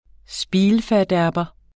Udtale [ ˈsbiːlfæɐ̯ˌdæɐ̯bʌ ]